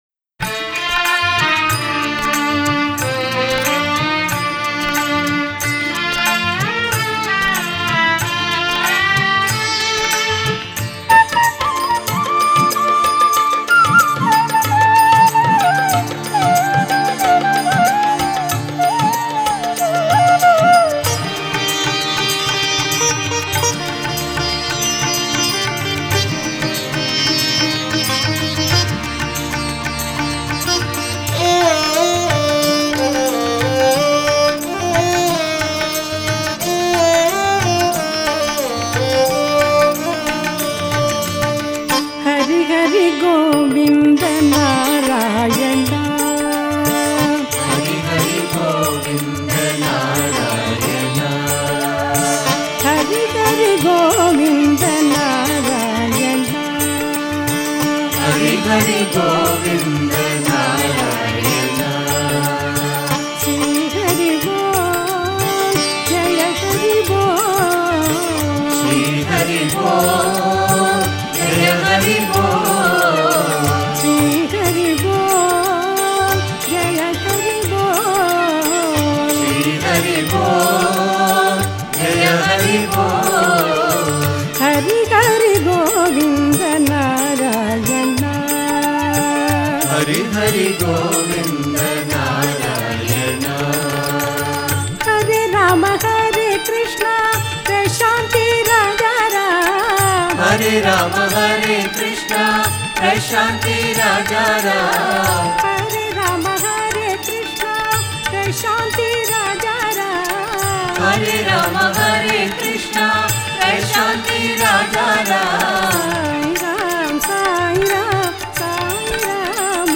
Home | Bhajan | Bhajans on various Deities | Narayana Bhajans | 13 HARI HARI GOVINDA NARAYANA